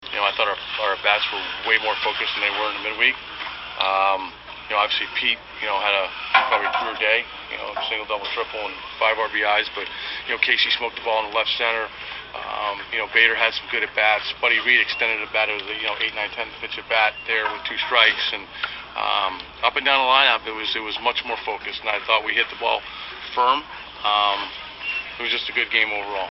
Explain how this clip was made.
Sound from the game can be heard below